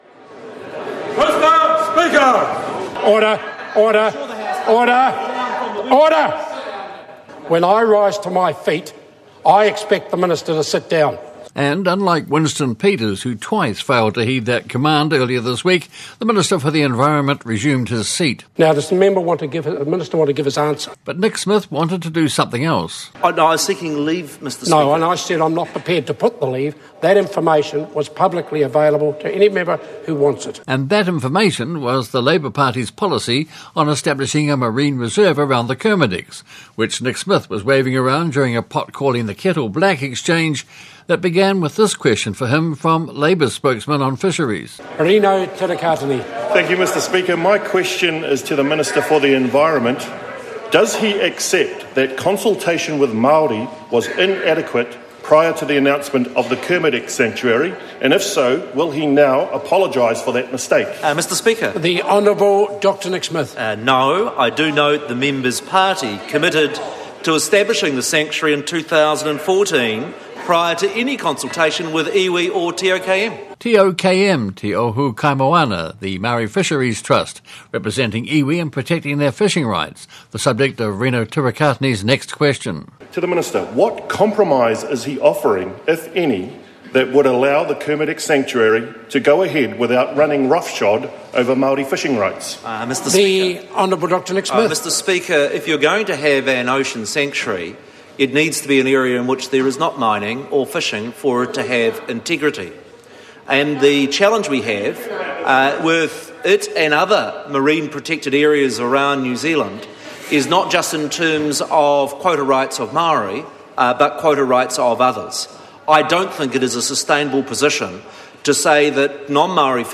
Environment Minister, Nick Smith, clashes with Labour’s fisheries spokesman, Rino Tirikatene, in Question Time over the Government’s proposal to establish a marine reserve around the Kermadecs.